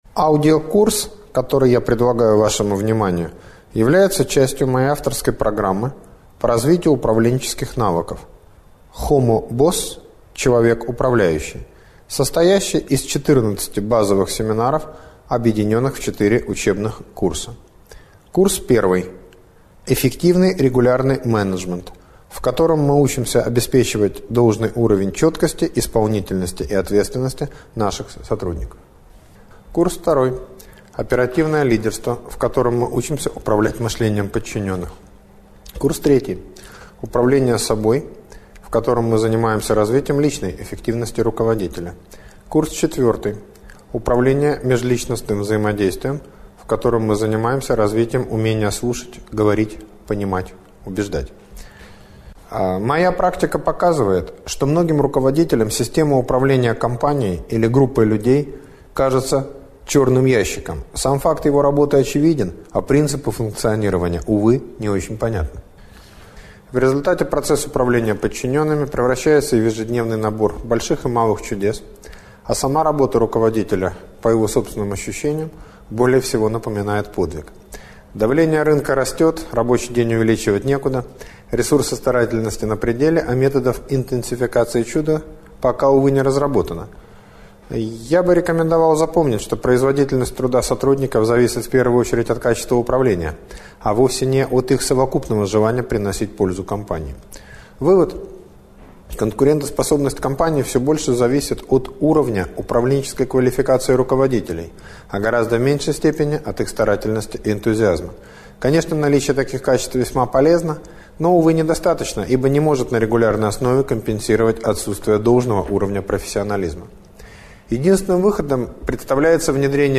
Аудиокнига Управление повседневным хаосом | Библиотека аудиокниг